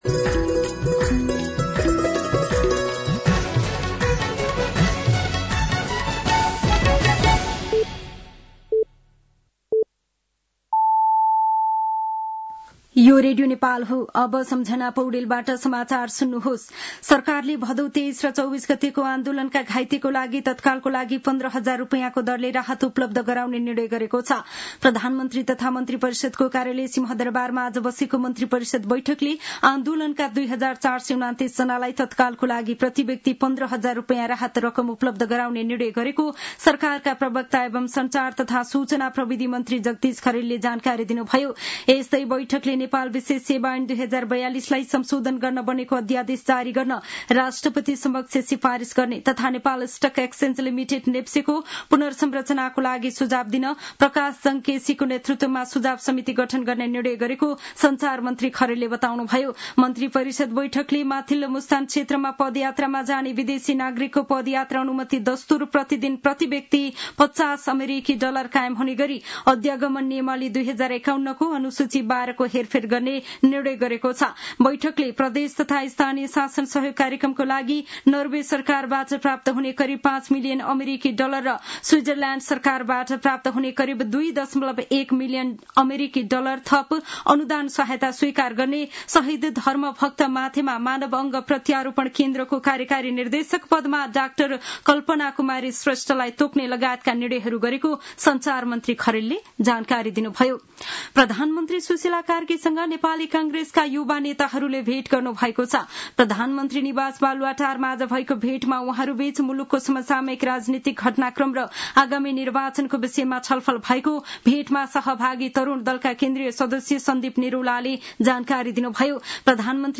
साँझ ५ बजेको नेपाली समाचार : २ मंसिर , २०८२